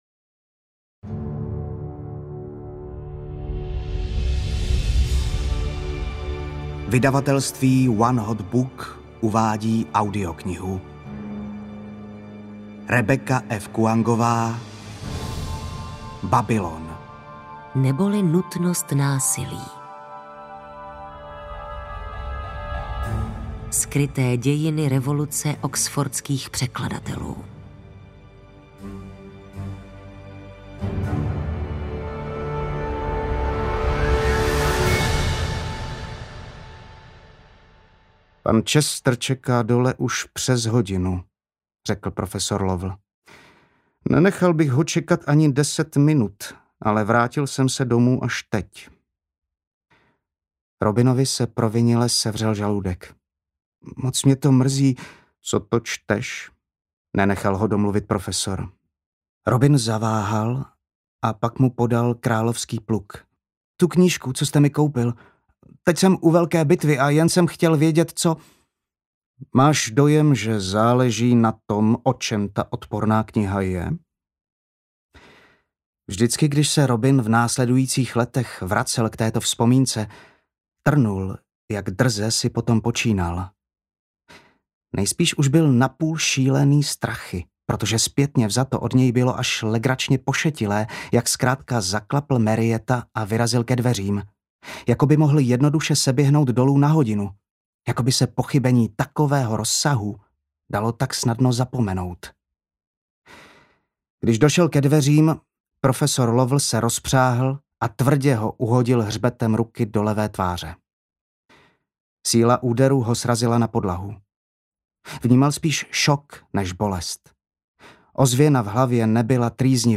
Babylon neboli Nutnost násilí audiokniha
Ukázka z knihy